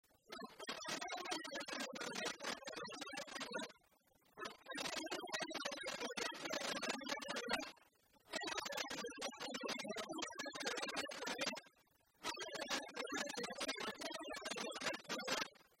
Mémoires et Patrimoines vivants - RaddO est une base de données d'archives iconographiques et sonores.
Résumé instrumental
danse-jeu : trompeuse
Pièce musicale inédite